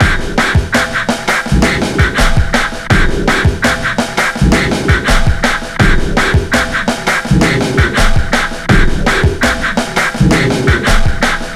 Index of /90_sSampleCDs/Best Service ProSamples vol.40 - Breakbeat 2 [AKAI] 1CD/Partition A/WEIRDBEAT083